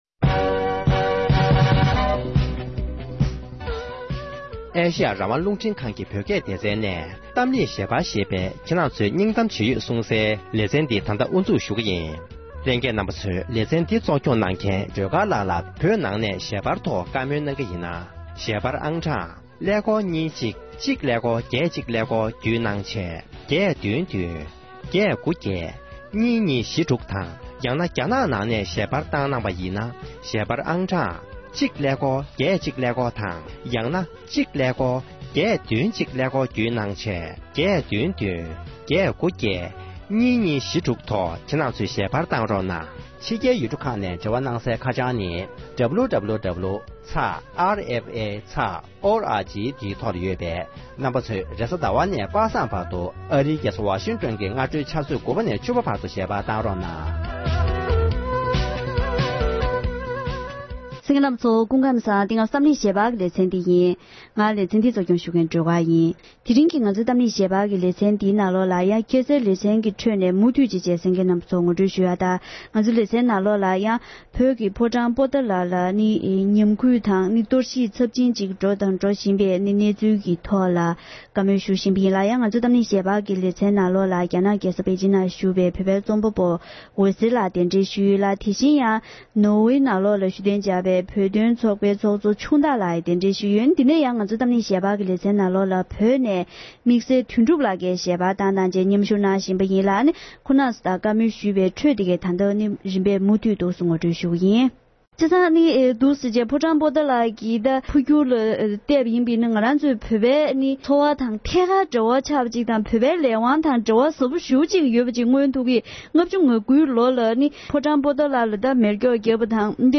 ༄༅༎དེ་རིང་གི་གཏམ་གླེང་ཞལ་པར་ཞེས་པའི་ལེ་ཚན་ནང་དུ།